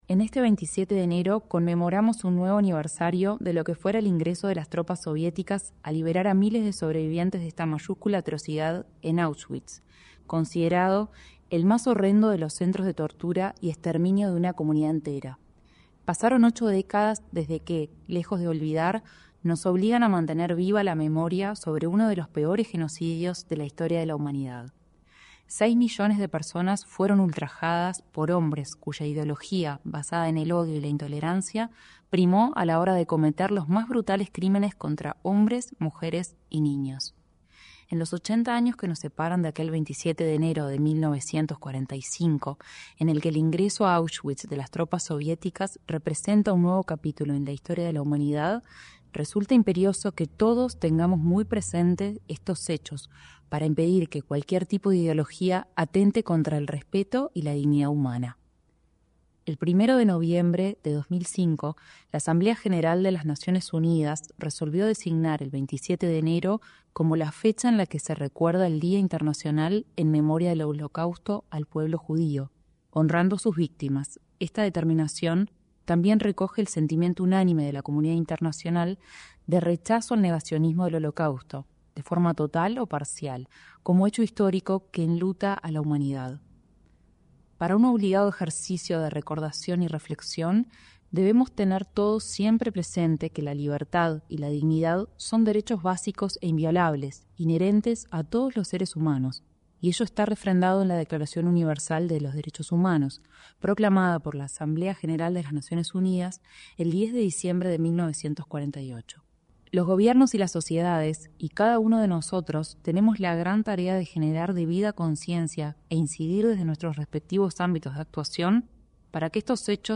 Mensaje en memoria de las víctimas del Holocausto 27/01/2025 Compartir Facebook X Copiar enlace WhatsApp LinkedIn La prosecretaria de la Presidencia, Mariana Cabrera, señaló, en cadena de radio y televisión, que el país continuará trabajando siempre, en el ámbito nacional y el internacional, para que hechos como el Holocausto no vuelvan a suceder.